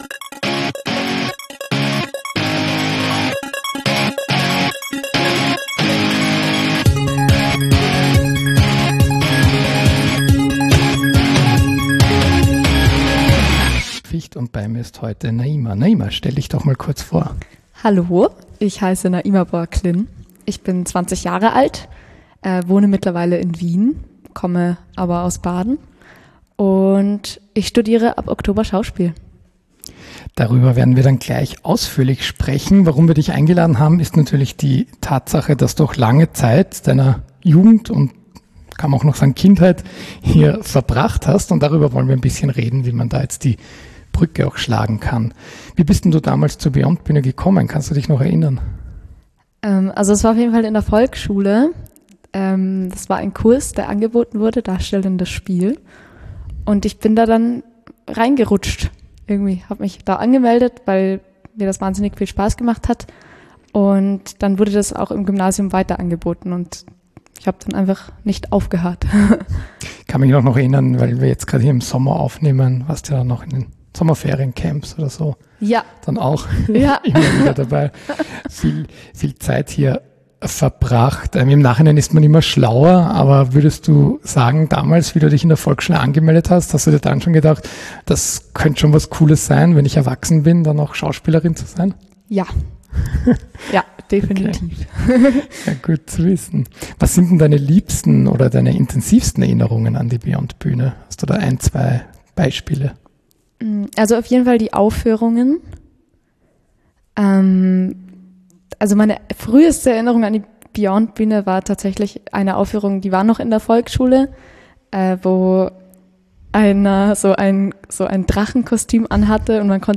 Interview mit unserem langjährigen Mitglied